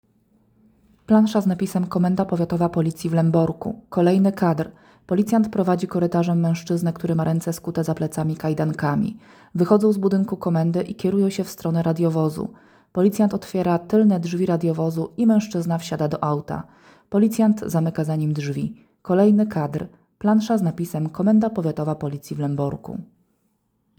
Nagranie audio Audiodeskrypcja1.m4a